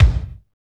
KIK F T K01R.wav